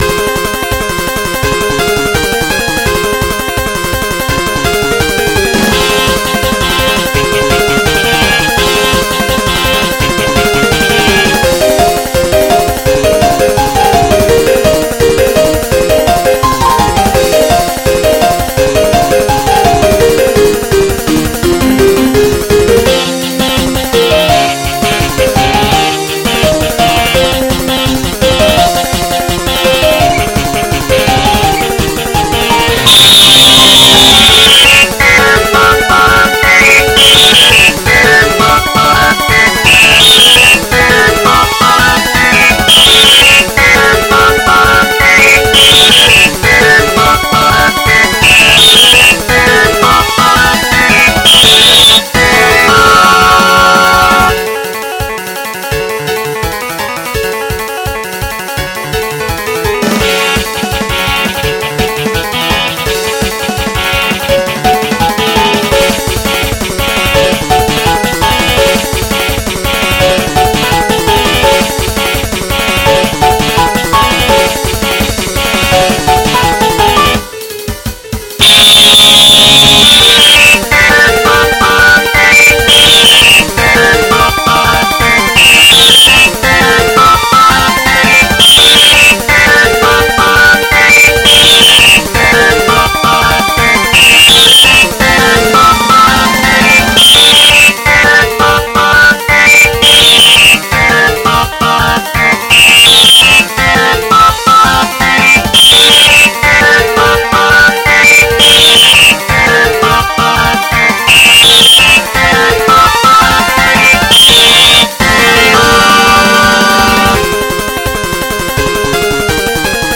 XG version